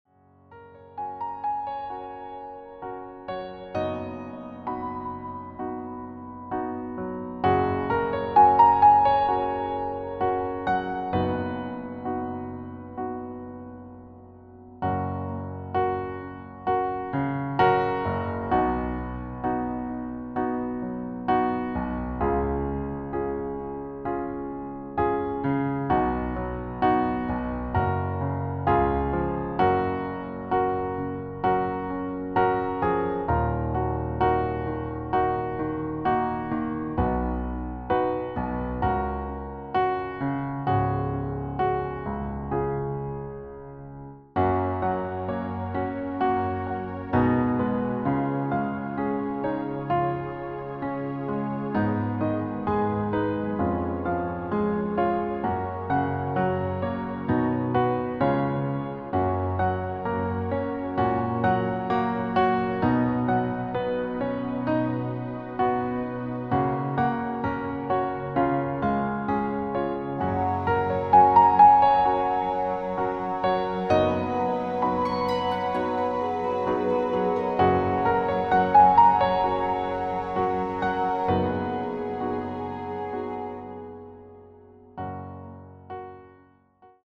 • Tonart: H Dur , E dur, C Dur , F# Dur
• Art: Klavier Streicher Version
• Das Instrumental beinhaltet NICHT die Leadstimme
Klavier / Streicher